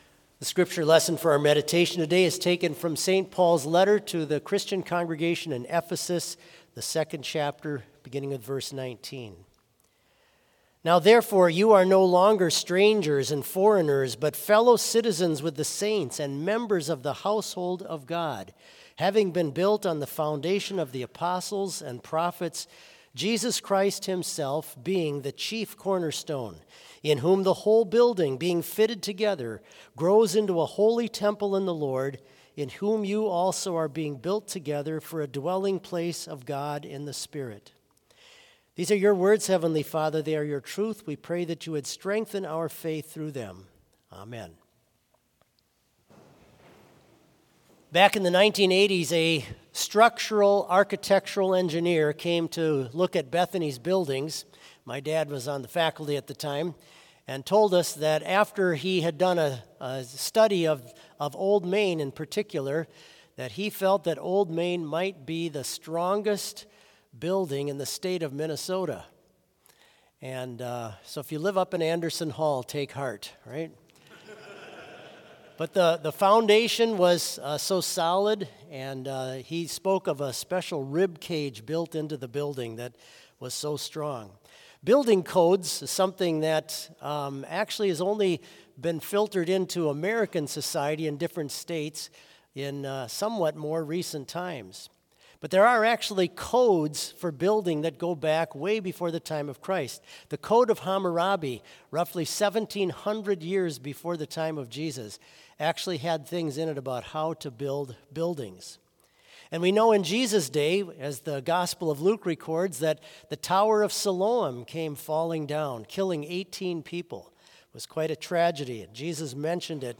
Complete Service
This Chapel Service was held in Trinity Chapel at Bethany Lutheran College on Wednesday, September 25, 2024, at 10 a.m. Page and hymn numbers are from the Evangelical Lutheran Hymnary.